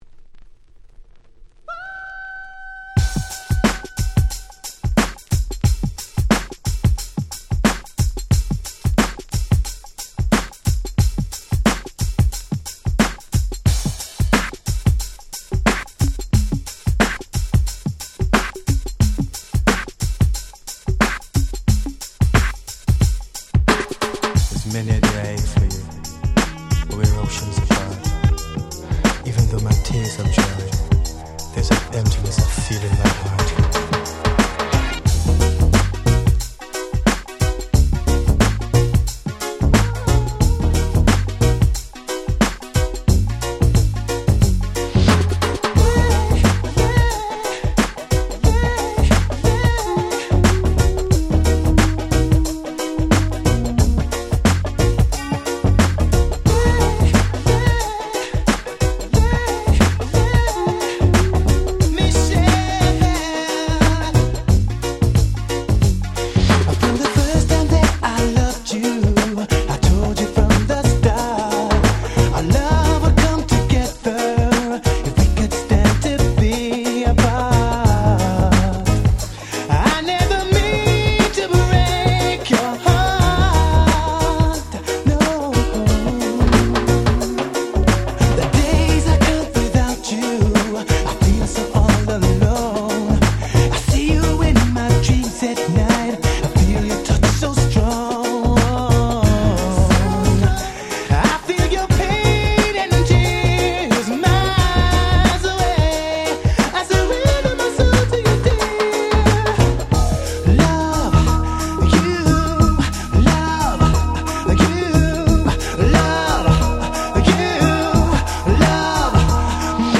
【Media】Vinyl 12'' Single (Promo)
ほんのりReggae風味の歌声に切ないメロディー、当時地味にヒットした1枚。
レゲエ